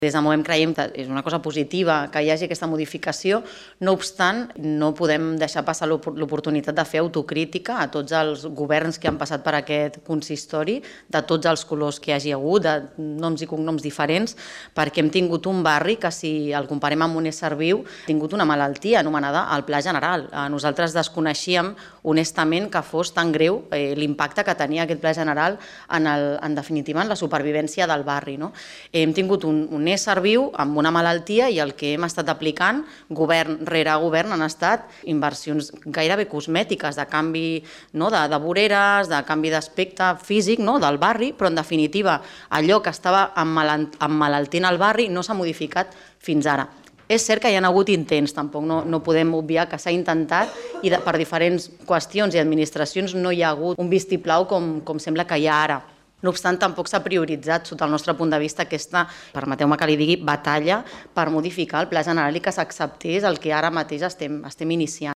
L’Ajuntament de Martorell va aprovar, en el Ple Ordinari d’ahir a la nit, una modificació puntual del Pla General d’Ordenació Urbana (PGOU) de Martorell de gran rellevància per La Vila.
Laura Ruiz, portaveu Movem Martorell